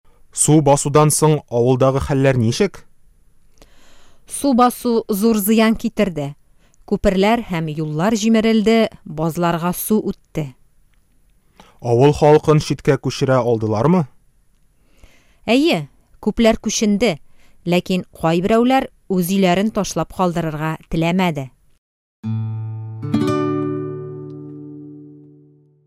Мы подготовили лексическую базу, предложения с примерами и озвучили тематические диалоги.
Диалог: Урман янгыны